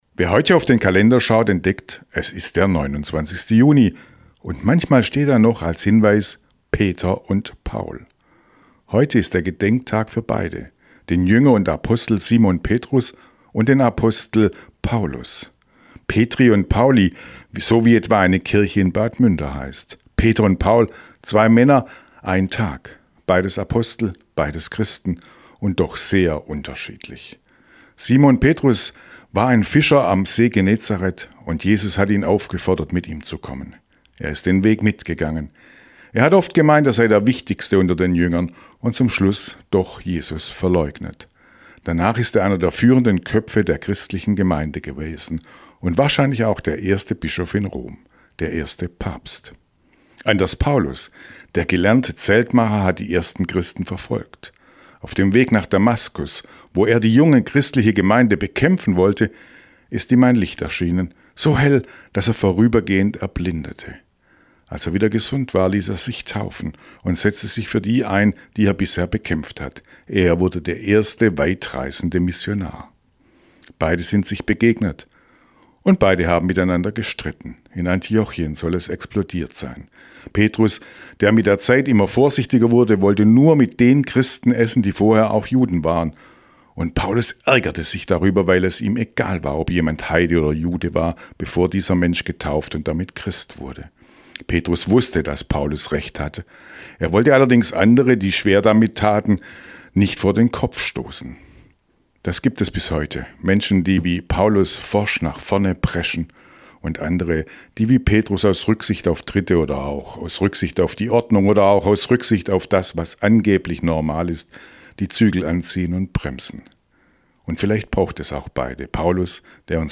Radioandacht vom 29. Juni